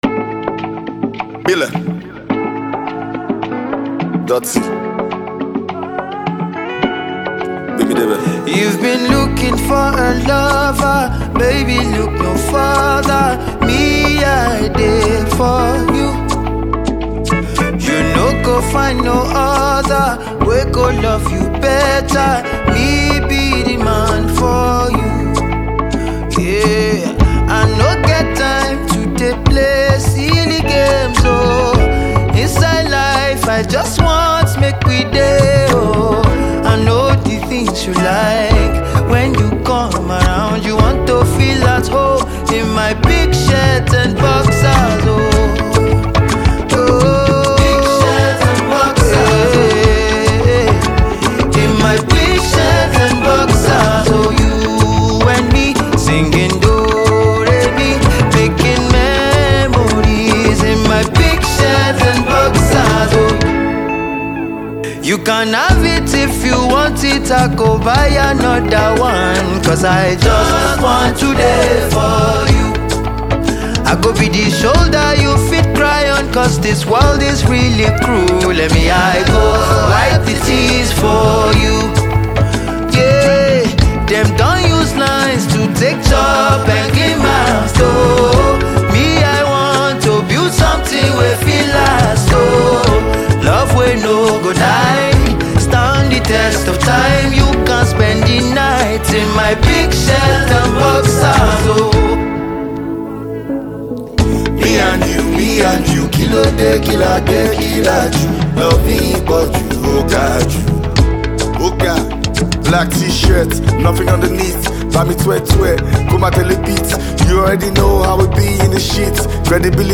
a legendary rapper from Nigeria.